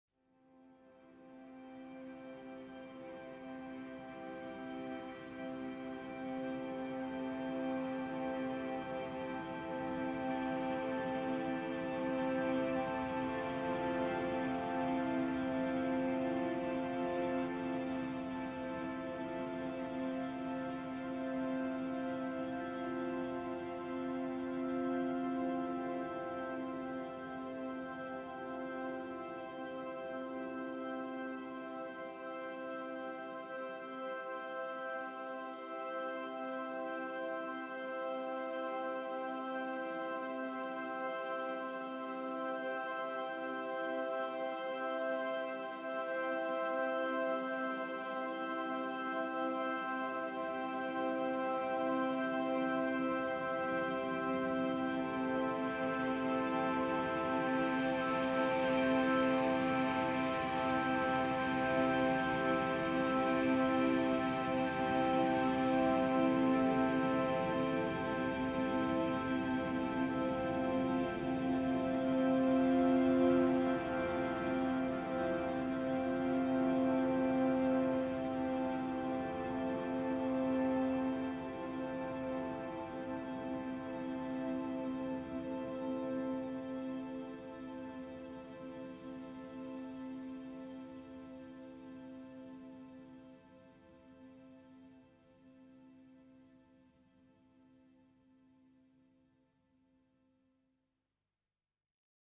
Synth Drone Version